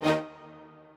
strings10_5.ogg